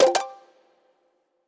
Alert Turn.wav